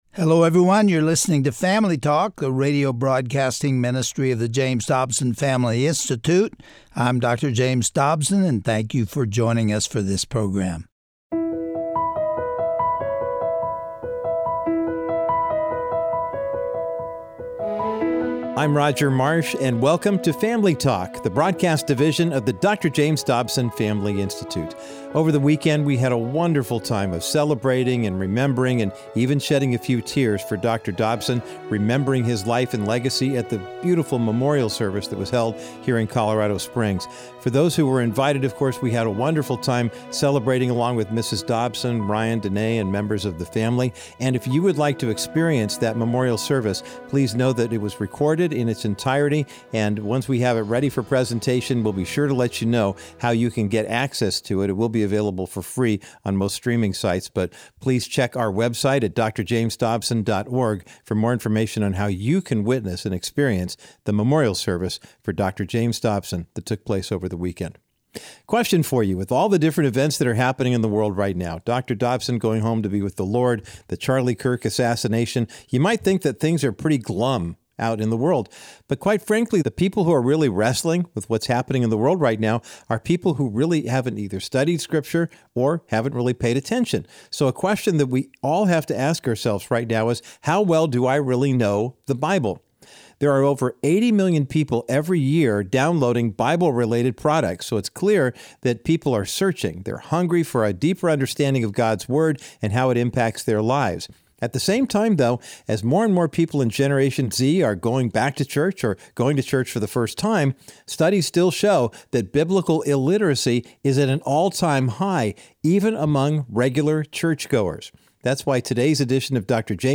Guest(s) Dr. Jack Graham